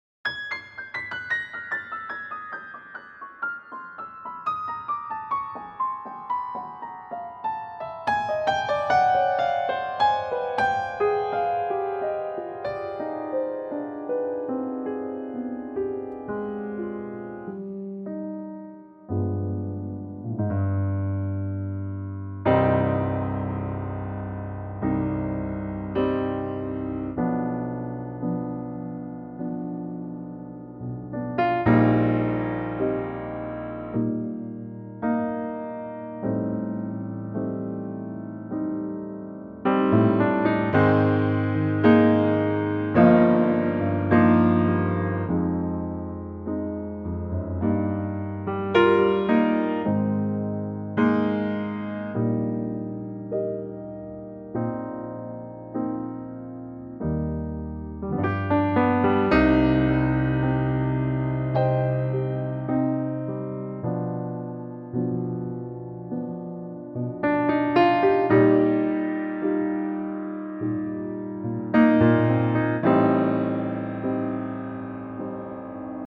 Unique Backing Tracks
key Cm
Suitable for higher male ranges.